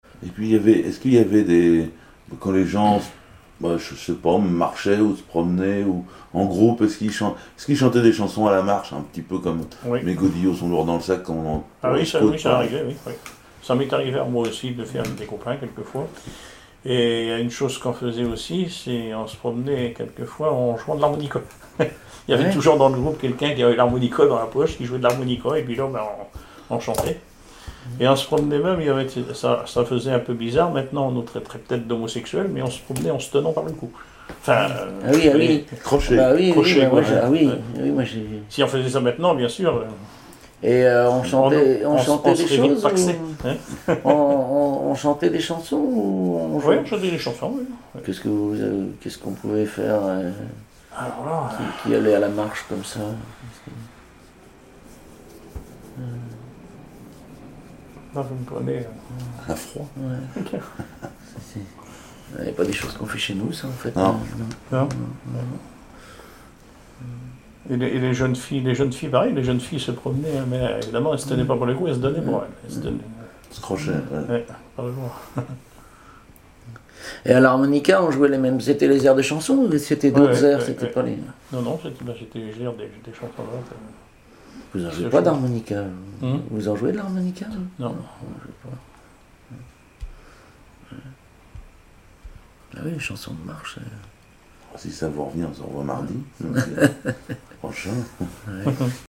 Témoignages sur le cycle calendaire et des extraits de chansons maritimes
Catégorie Témoignage